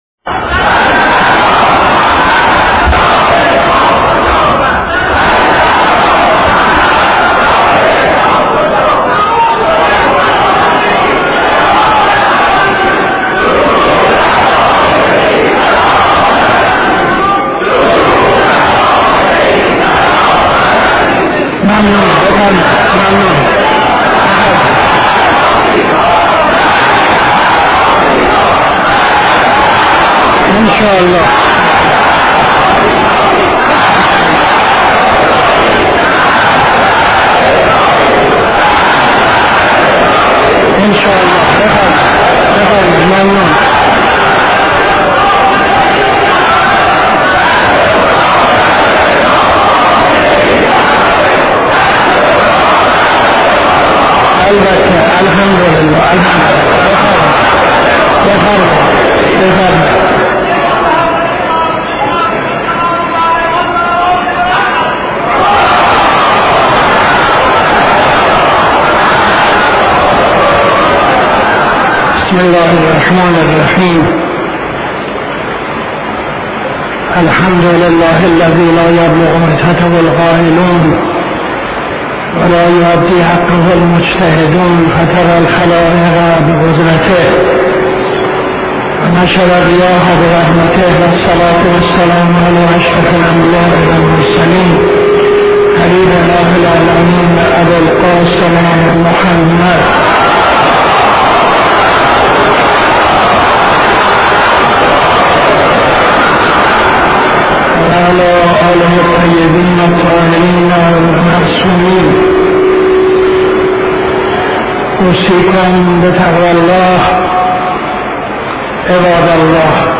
خطبه اول نماز جمعه 01-05-78